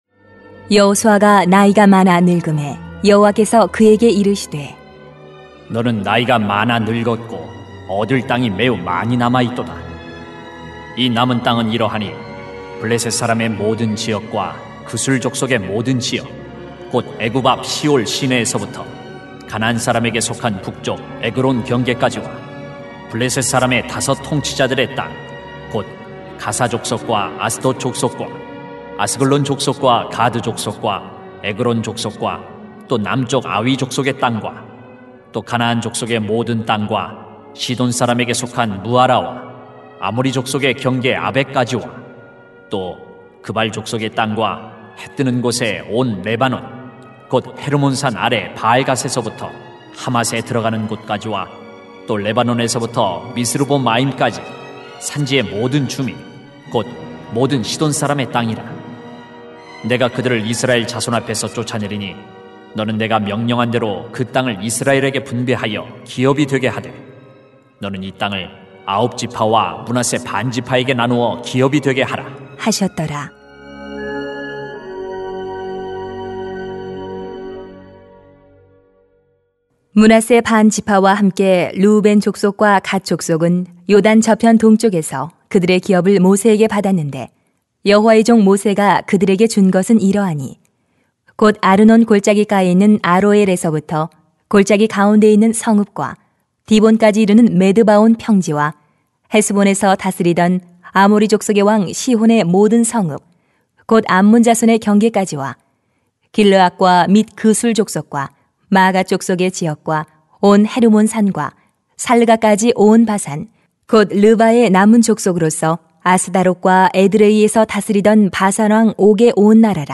[수 13:1-14] 내가 그들을 쫓아내리라 > 새벽기도회 | 전주제자교회